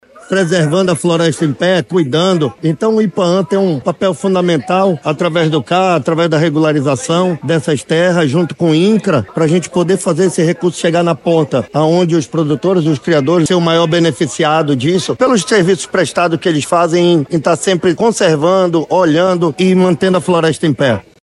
Realizado em parceria com o Programa Floresta+ Amazônia, iniciativa do Ministério do Meio Ambiente e Mudança do Clima (MMA) e do Programa das Nações Unidas para o Desenvolvimento (PNUD), o projeto visa não apenas a regularização ambiental das propriedades rurais, renda, mas também o incentivo à conservação da floresta, reconhecendo e apoiando produtores que adotam práticas sustentáveis, como destaca o diretor-presidente do Ipaam, Gustavo Picanço.